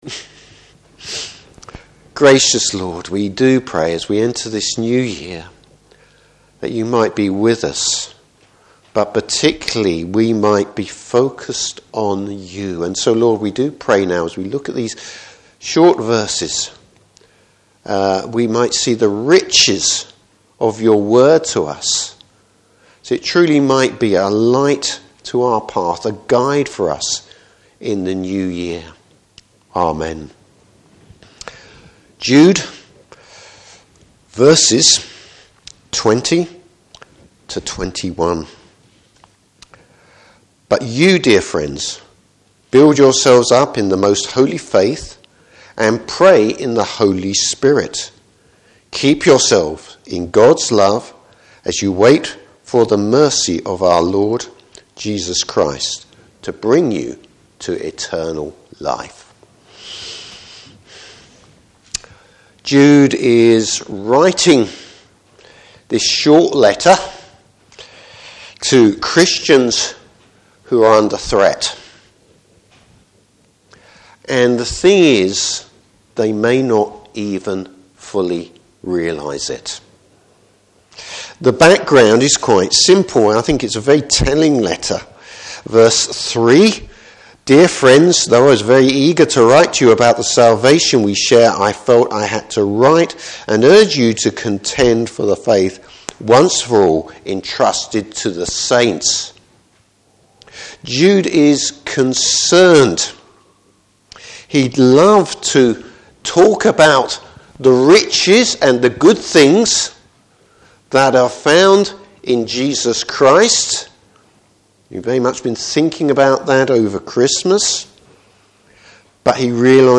Series: New Years Sermon.
Service Type: Morning Service Taking care of your spiritual life.